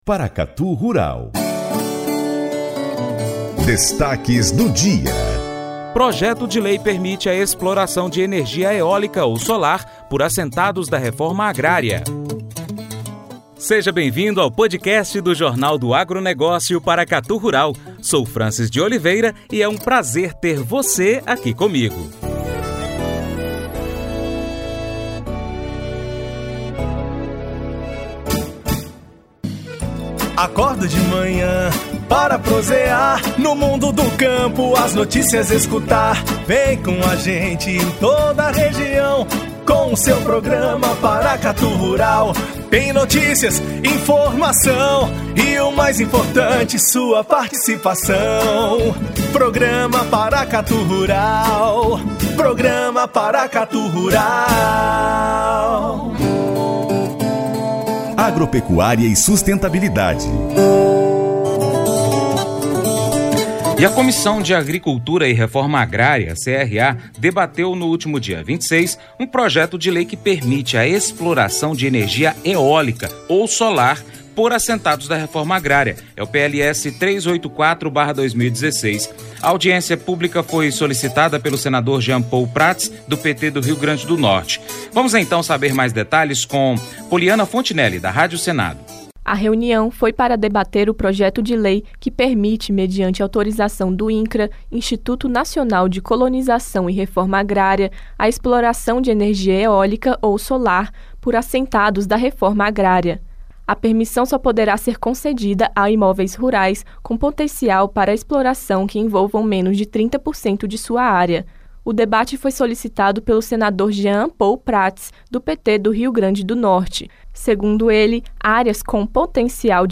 A audiência pública foi solicitada pelo senador Jean Paul Prates (PT-RN).